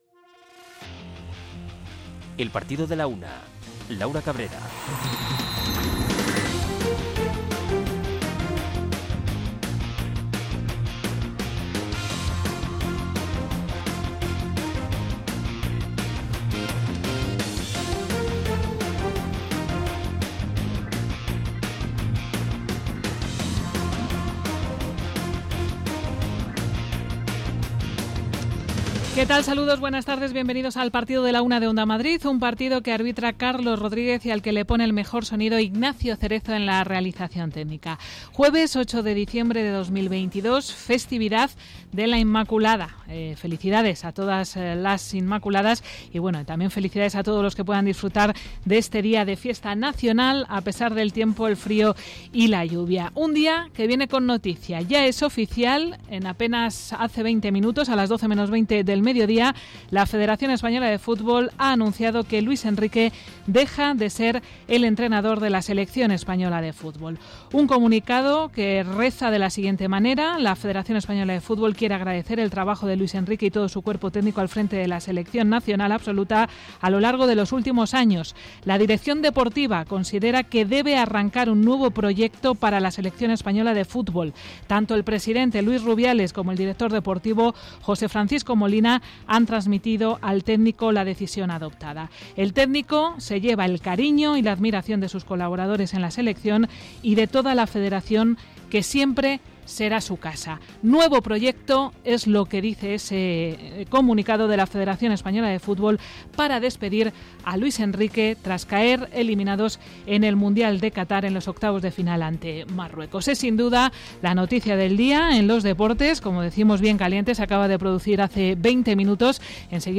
Es la referencia diaria de la actualidad deportiva local, regional, nacional e internacional. El rigor en la información y el análisis medido de los contenidos, con entrevistas, reportajes, conexiones en directo y el repaso a la agenda polideportiva de cada día, son la esencia de este programa.